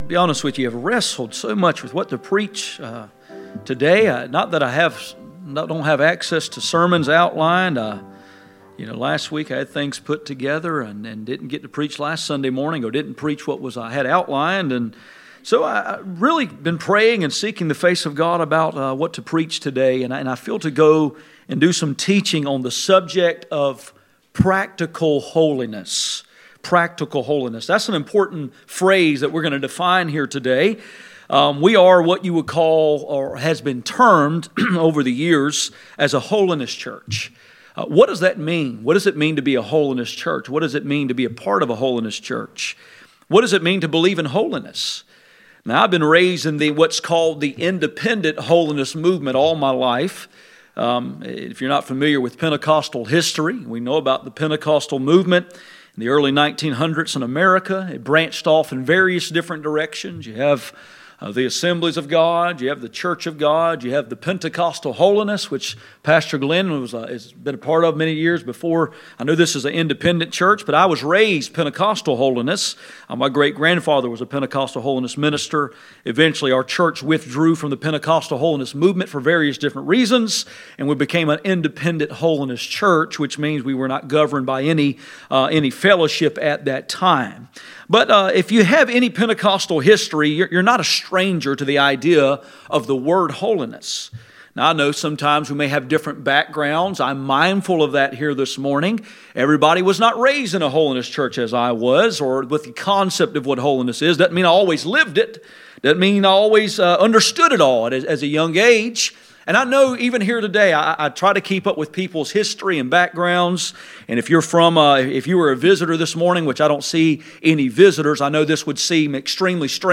None Passage: Hebrews 12:14 Service Type: Sunday Morning %todo_render% « Abraham the Warrior and the Worshipper Practical Holiness